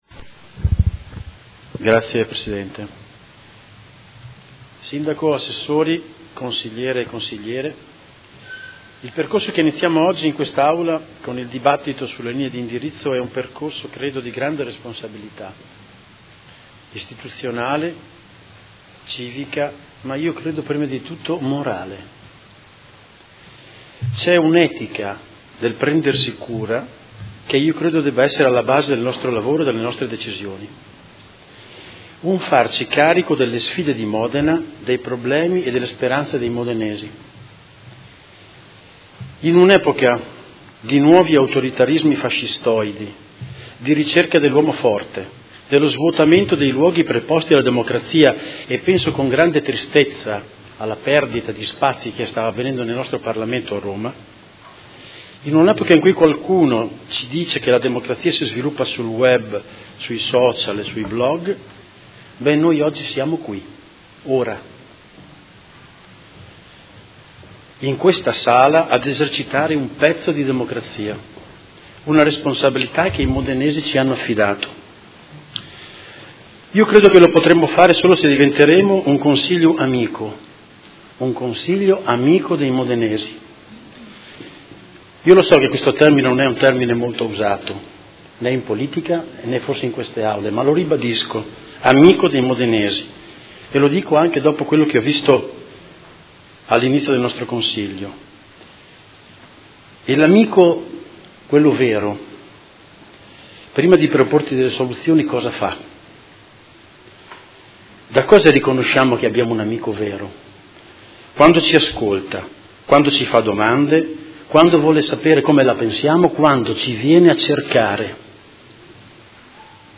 Alberto Cirelli — Sito Audio Consiglio Comunale
Seduta del 20/06/2019. Dibattito su proposta di deliberazione: Indirizzi Generali di Governo 2019-2024 - Discussione e votazione